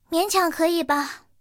萤火虫-拉德利强化语音.OGG